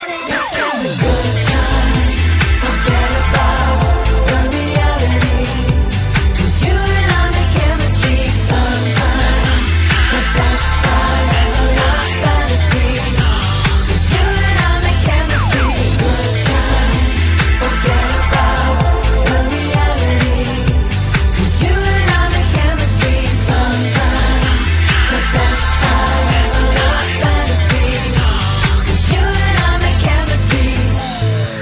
syn-drum filled